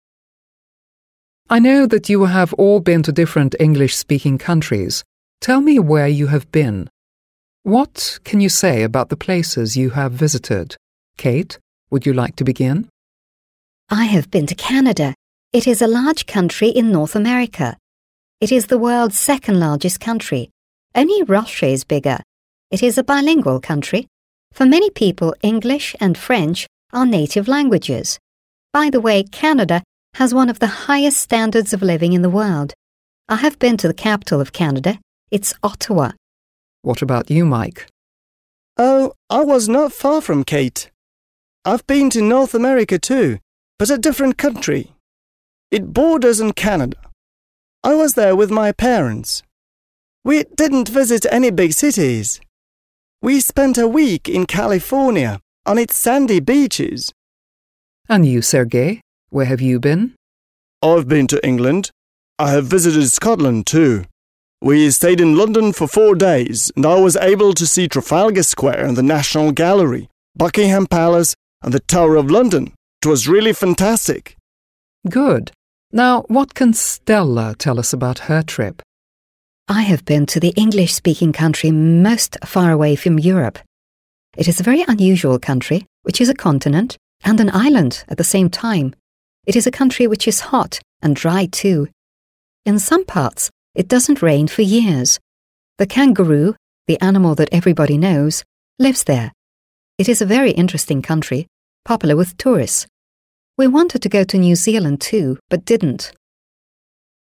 Listen and match the names of the speakers (1—4) with the statements they make (a—e).